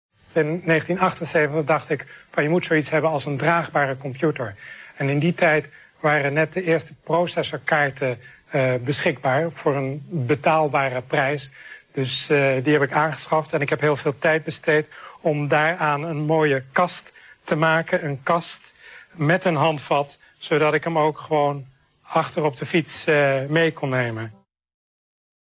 gesproken commentaar